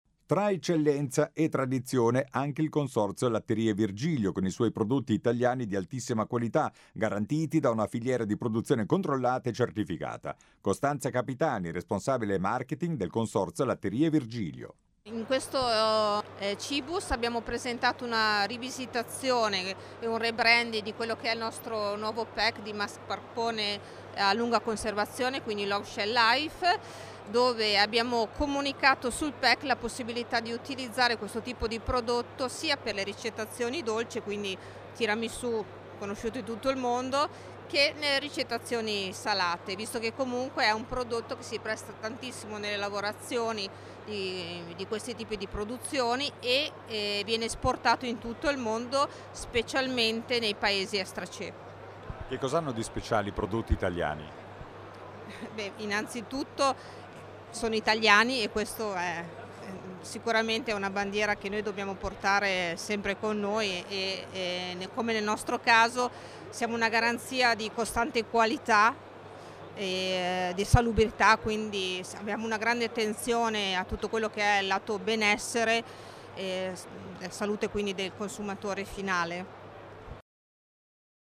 Qui di seguito le dichiarazioni raccolte dal nostro inviato sul posto